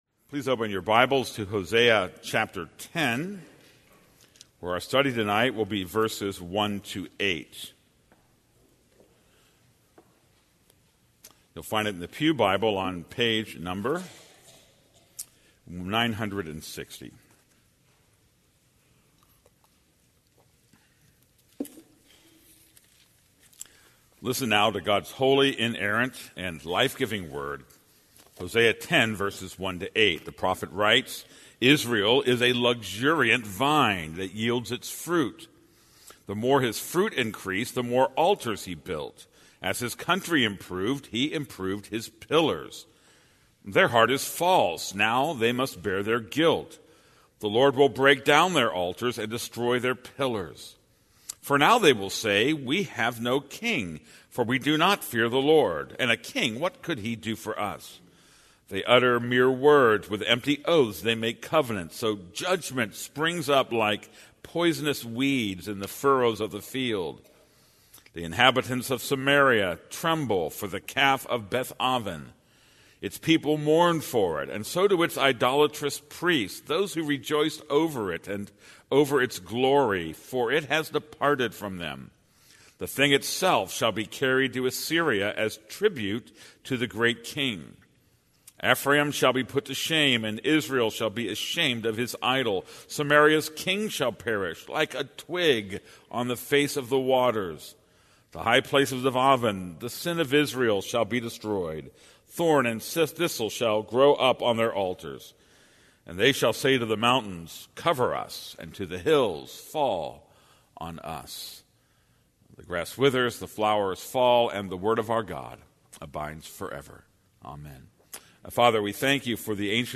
This is a sermon on Hosea 10:1-8.